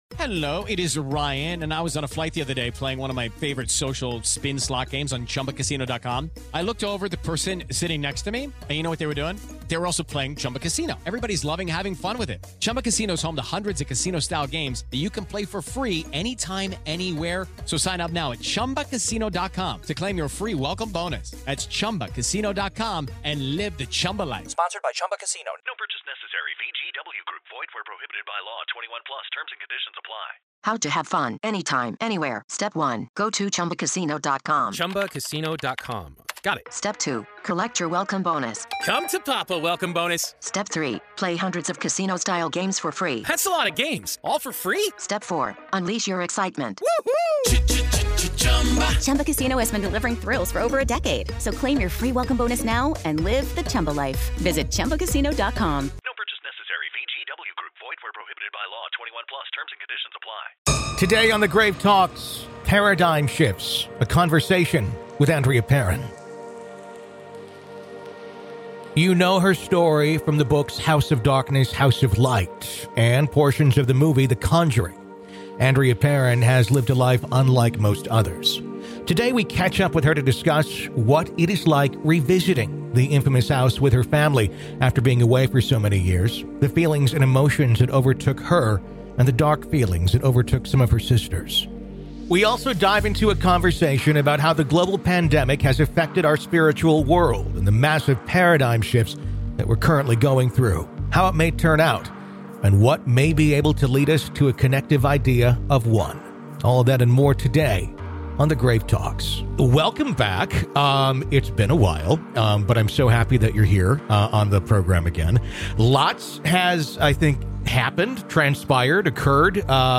We also dive into a conversation about how the global pandemic has affected our spiritual world and the massive paradigm shifts that we are currently going through, how it may turn out, and what may be able to lead us to a connective idea of “one.”